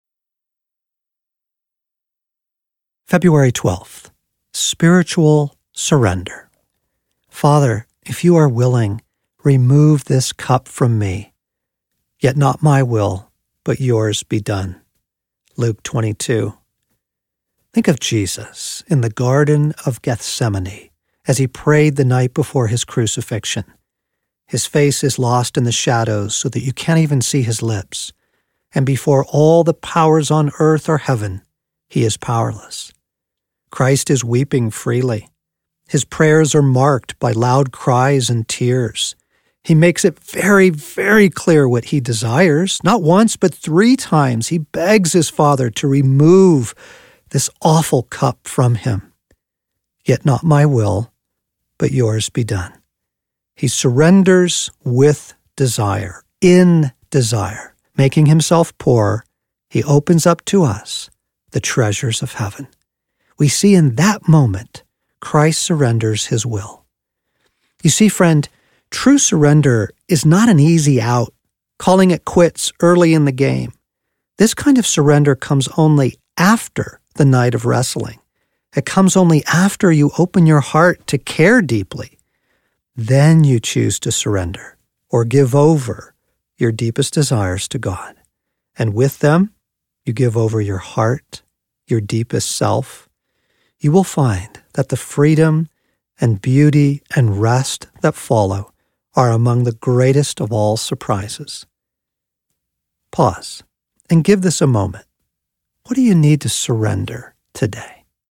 Restoration Year Audiobook